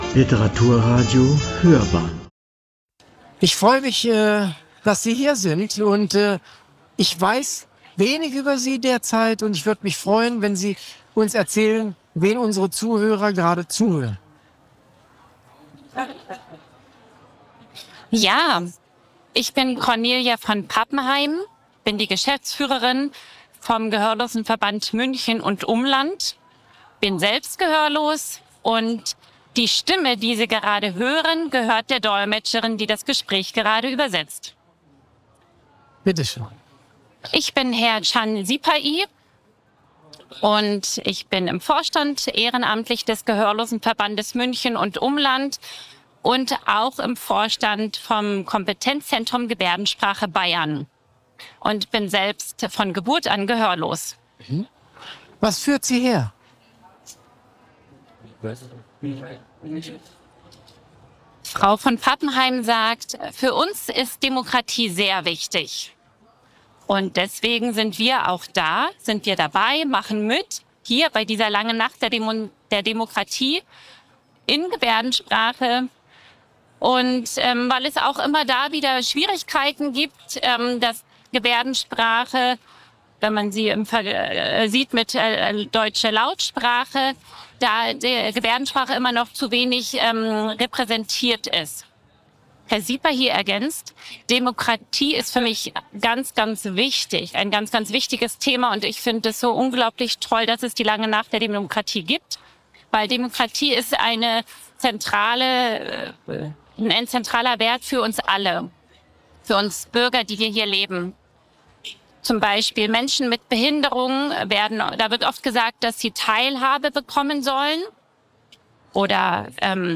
Konnten wir Mitbürgerinnen und Mitbürger ohne Gehör, vom Gehörlosenverband München und Umland e.V., zum Thema Demokratie für das Radio interviewen?